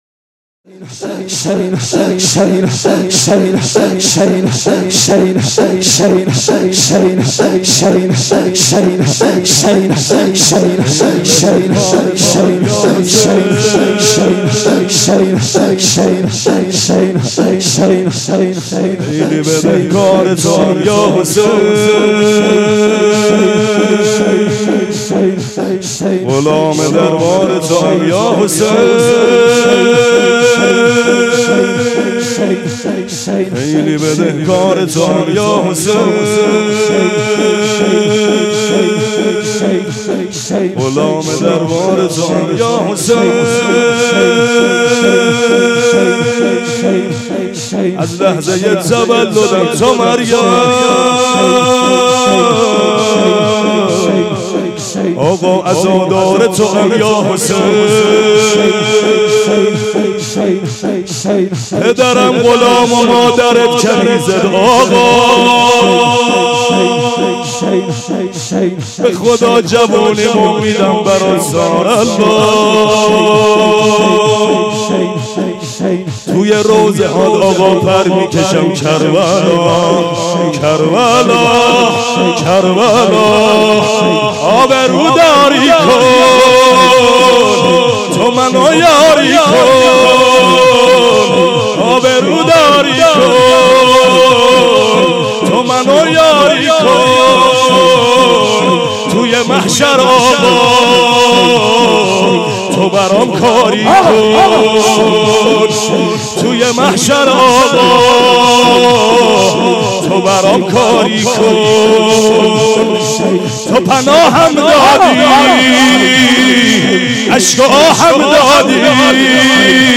بشنوید/ شب شهادت امام باقر(ع) با مداحی حاج محمود کریمی در مسجد حضرت امیر(ع)